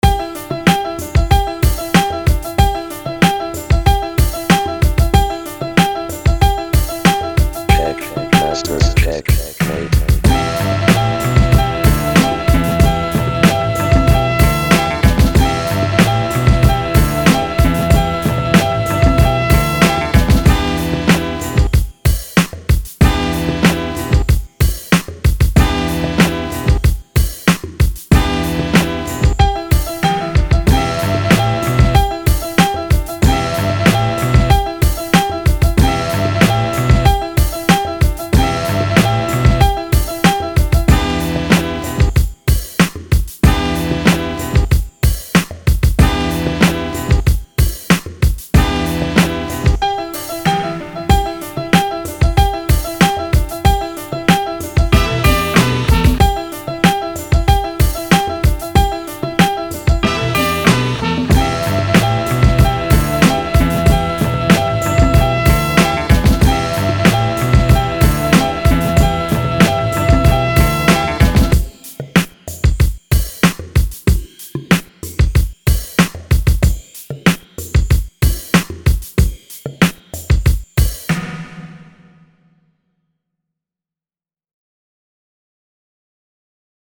그래서 이퀼라이저와 컴프레서와 각종 EQ를 이용해서
소울 샘플을 드럼에 맞는 수준까지 소리를 때깔나게 만들어 봤습니다.
드럼은 MPC3000+TR808입니다.
MPC3000에 내장 음원입니다.